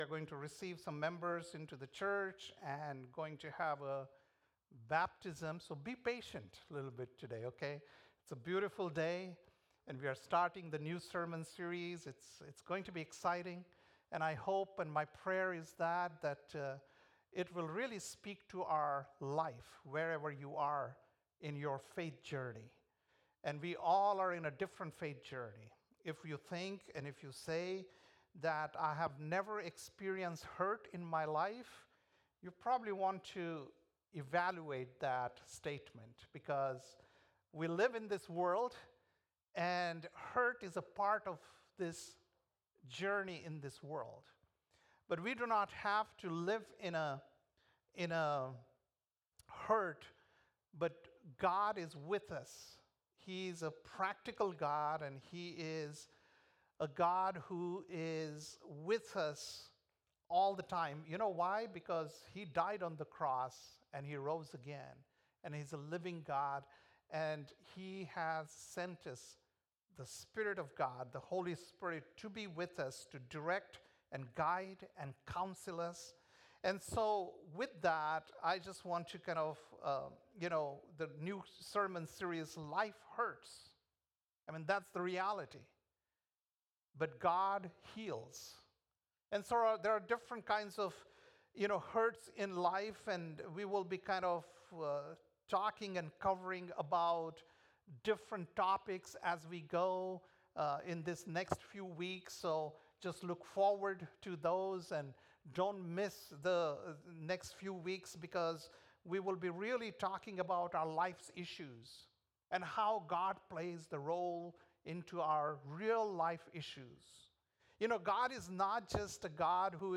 February 2nd, 2025 - Sunday Service - Wasilla Lake Church